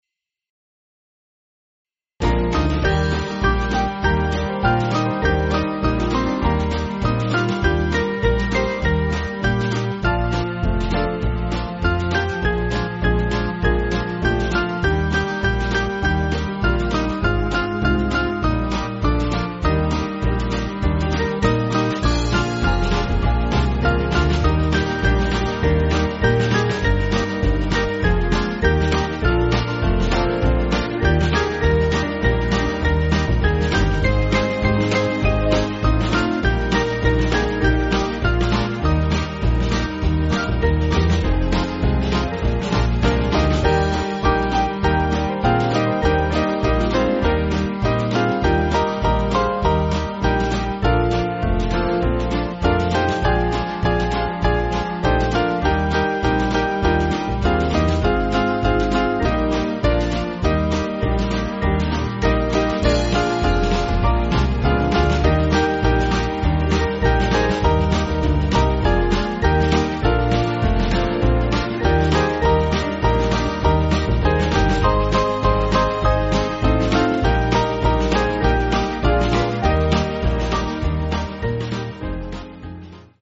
Small Band
(CM)   4/Eb